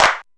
DX Clap 01.wav